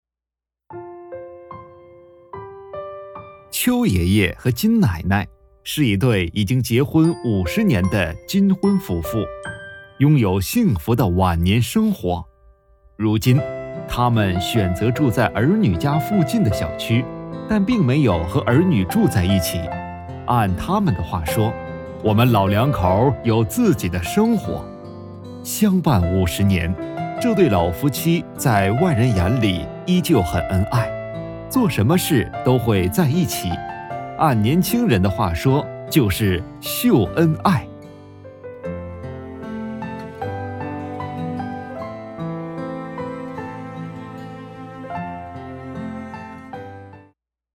纪录片-男58-人物纪录.mp3